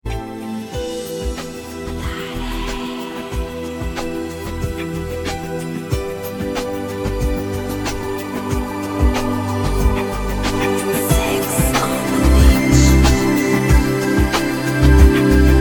• Качество: 192, Stereo
атмосферные
спокойные
загадочные
цикличные